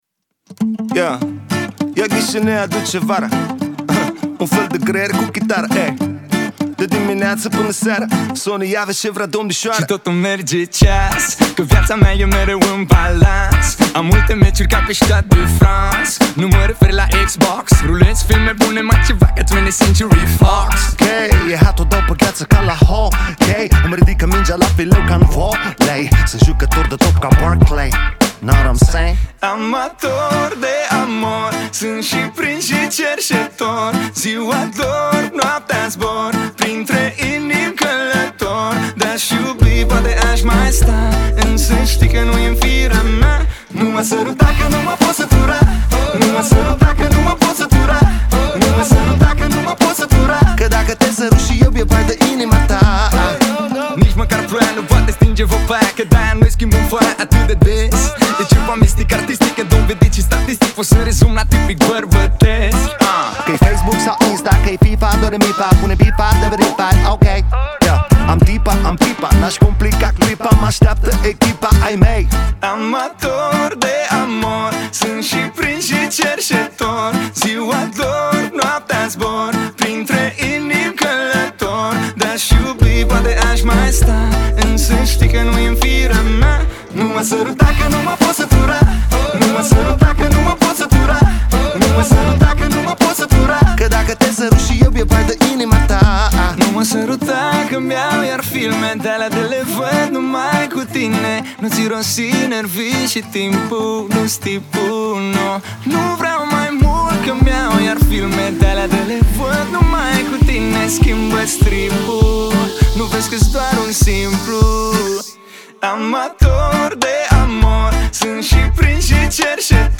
это зажигательная композиция в жанре поп-музыки